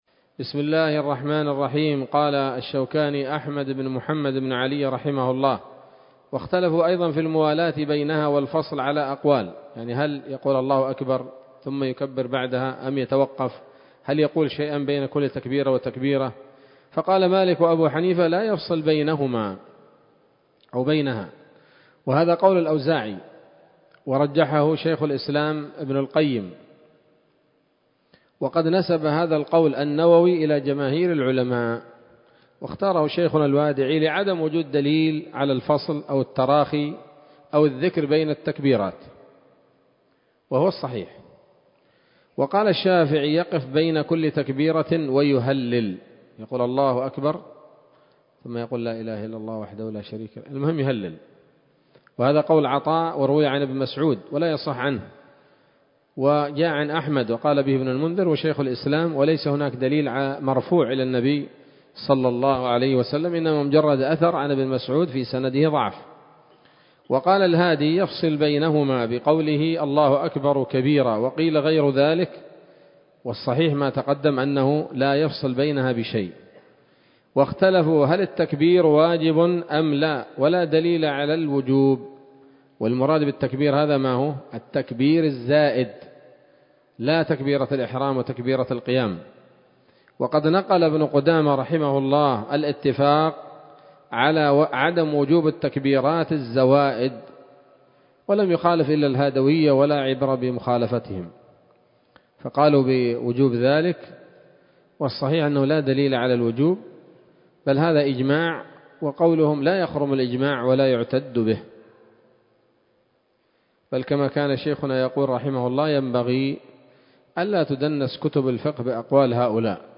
الدرس السابع والأربعون من كتاب الصلاة من السموط الذهبية الحاوية للدرر البهية